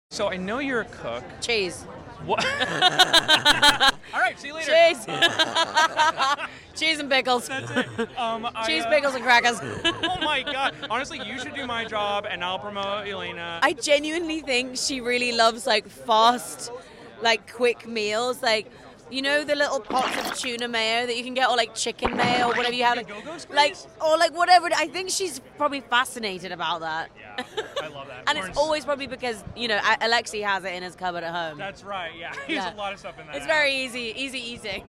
Florence Pugh's laugh is AMAZING. sound effects free download
You Just Search Sound Effects And Download. tiktok laughing sound effects Download Sound Effect Home